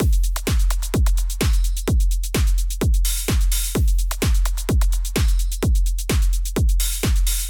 ドラム
ここで、低域のサウンドが少しLRににじんでいるのが気になりました。
Mono Makerを使うと指定帯域以下をモノラル化することができます。
いい具合に締まっていますね。